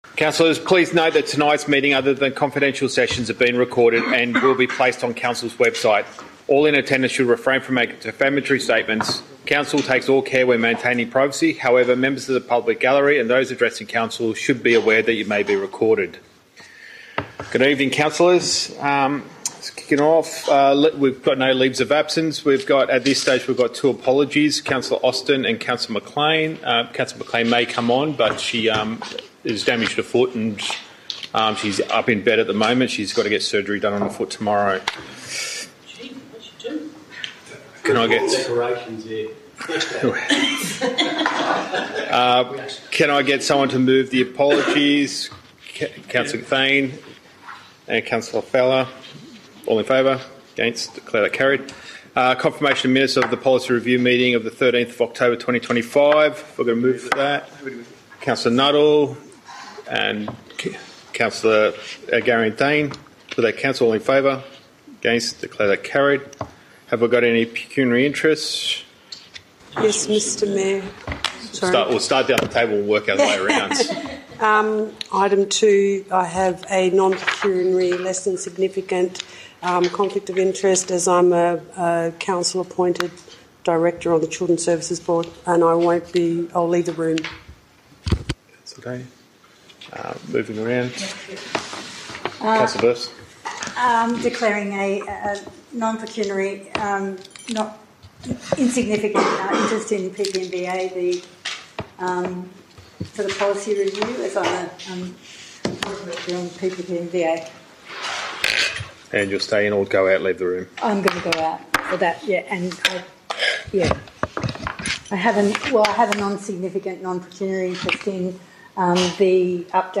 Archive of Penrith City Council Meetings.